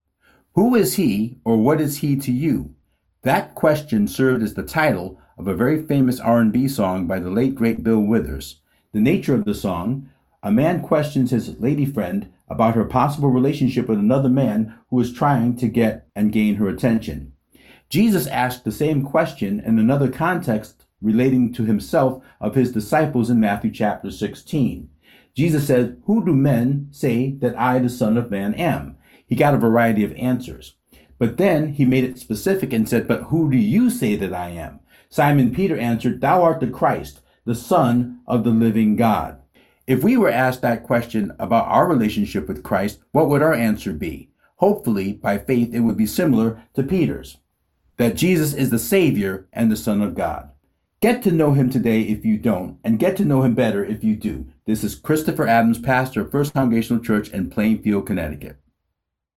One Minute inspirational thoughts presented by various clergy!